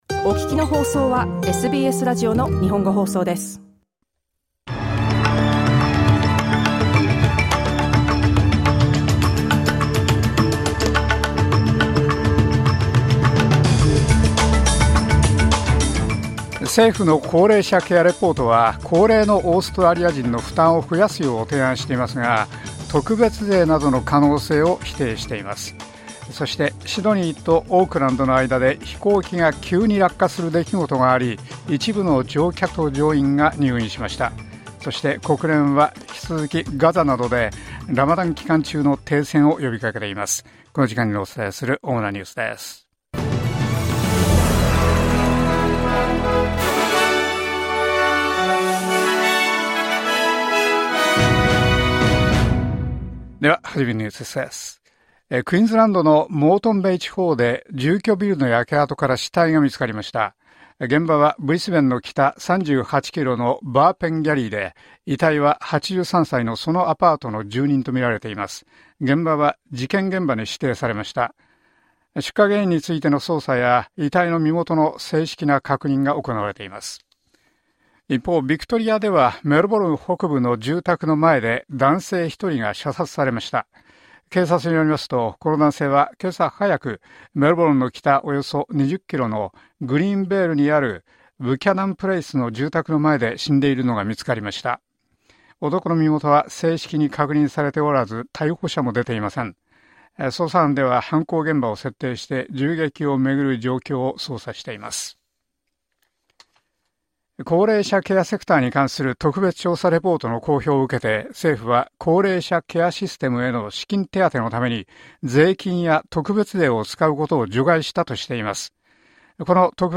SBS日本語放送ニュース３月12日火曜日